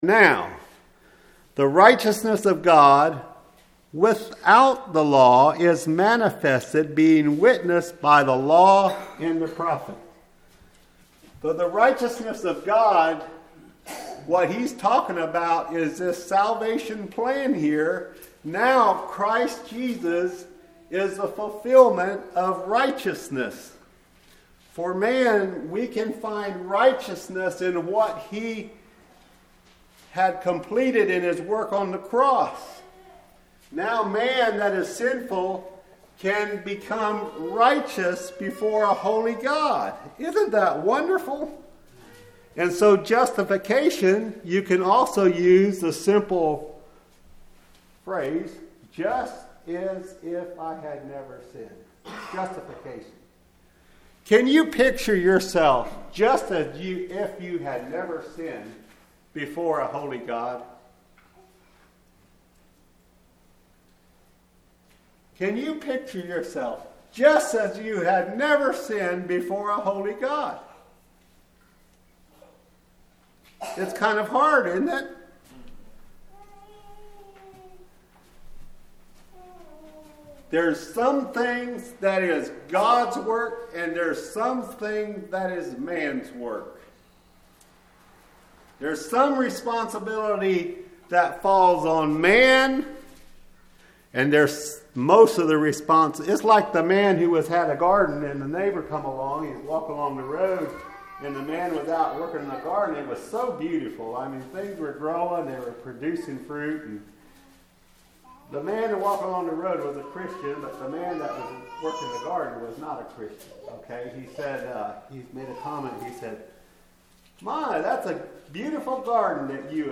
Sermons
Hidden River | Revivals 2025